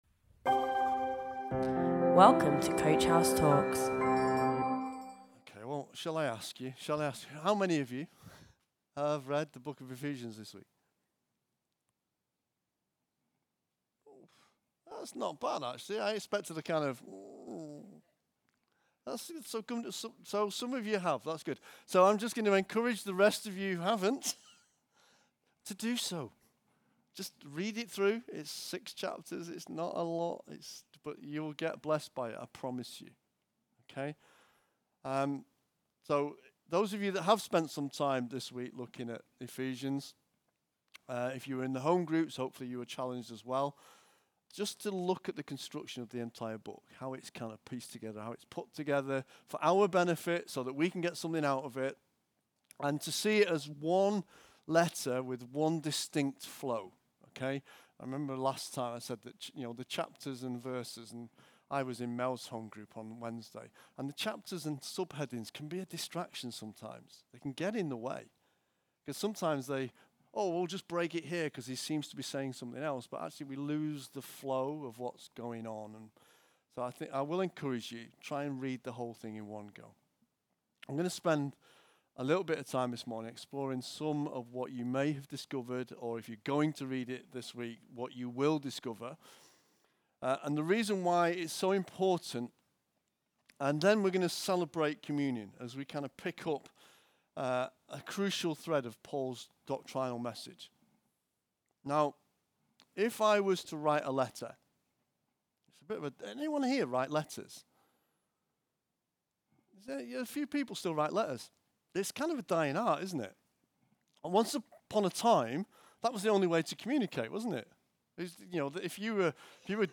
SUNDAY SERVICE // Justice Without Christ